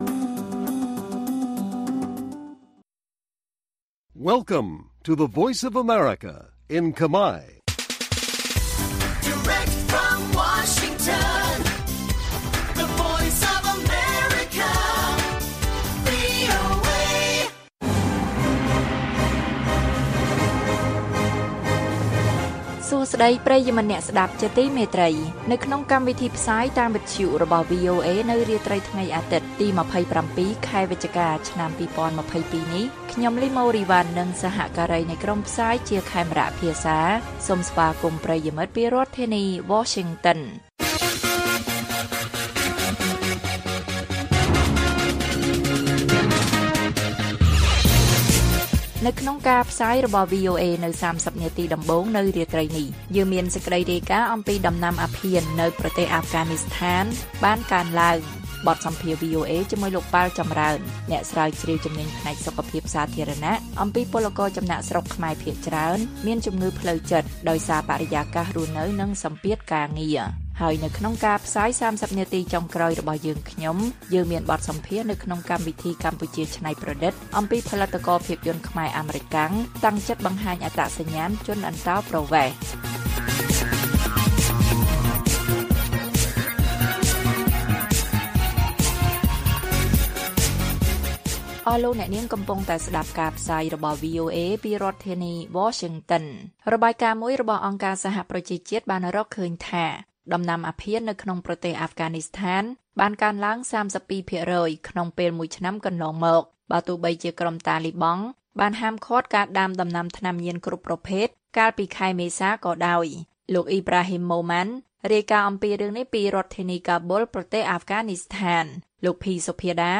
ព័ត៌មាននៅថ្ងៃនេះមានដូចជា ដំណាំអាភៀននៅអាហ្វហ្គានីស្ថានបានកើនឡើង។ បទសម្ភាសន៍ VOA៖ ពលករចំណាកស្រុកខ្មែរភាគច្រើនមានជំងឺផ្លូវចិត្តដោយសារបរិយាកាសរស់នៅនិងសម្ពាធការងារ។ បទសម្ភាសន៍កម្ពុជាច្នៃប្រតិដ្ឋ៖ ផលិតករភាពយន្តខ្មែរអាមេរិកាំងតាំងចិត្តបង្ហាញអត្តសញ្ញាណជនអន្តោប្រវេសន៍៕